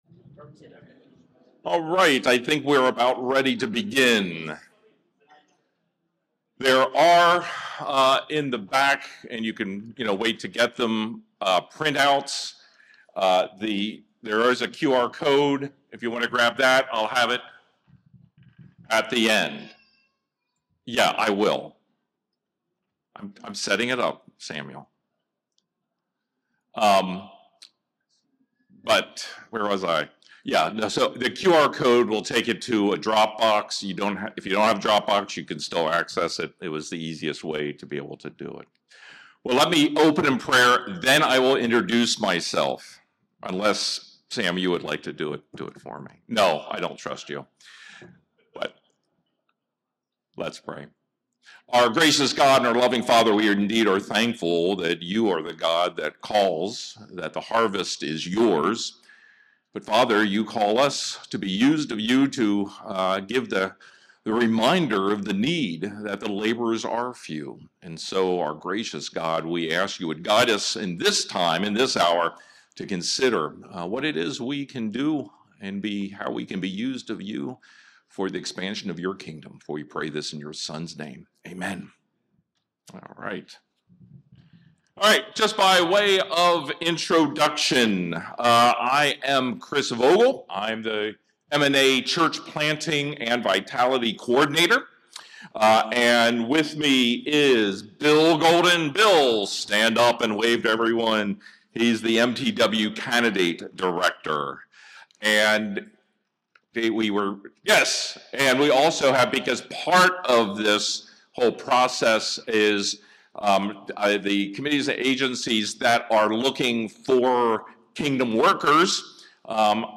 This seminar is a new joint venture by MNA and MTW toward that end. It offers a curriculum to assist churches in preparing their members to consider how their callings may be helpful as volunteers, part-time, or full-time workers in the PCA Listen Online Here